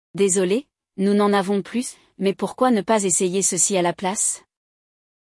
No episódio de hoje, acompanhamos um diálogo onde nossa personagem enfrenta exatamente esse problema e recebe uma sugestão inesperada do crémier.